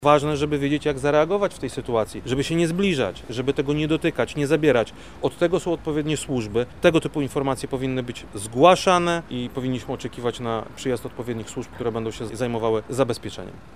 O tym jak zachować się w przypadku znalezienia części maszyn mówi wojewoda lubelski Krzysztof Komorski: